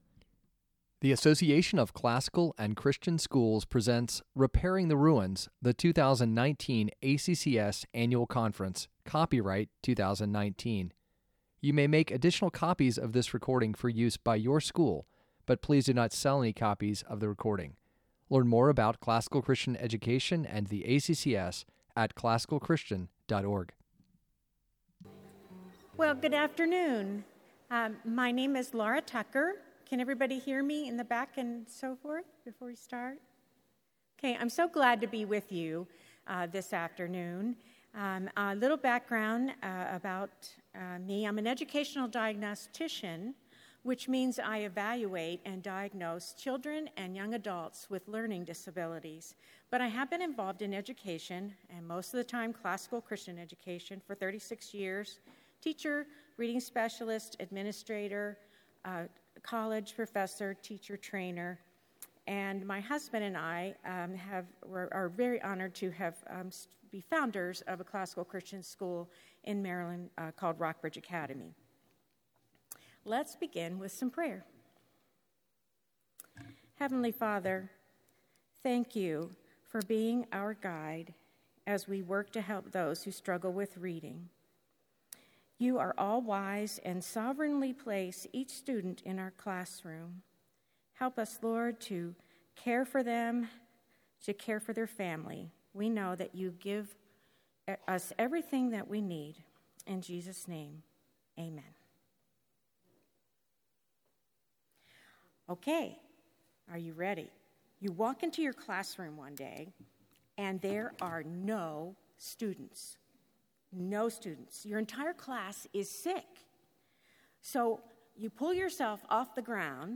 2019 Workshop Talk | 01:02:35 | K-6, Student Products & Services, Literature